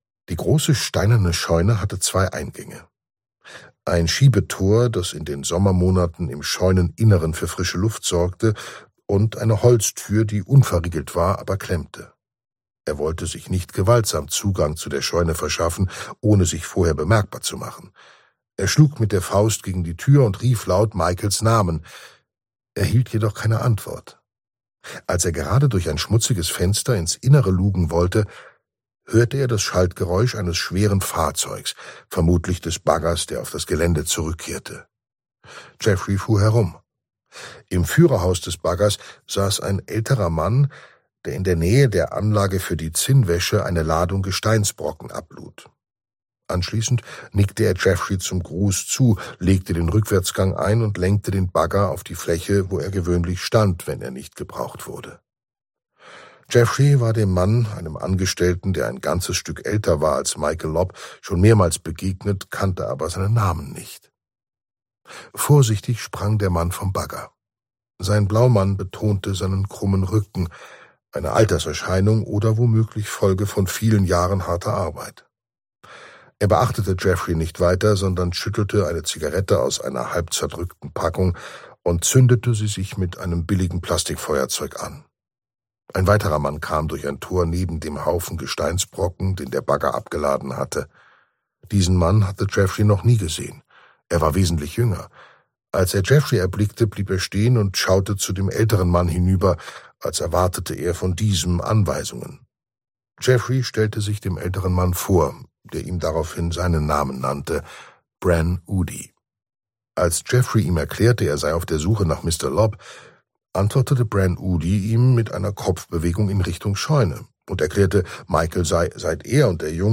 Gekürzte Lesung